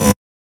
edm-perc-48.wav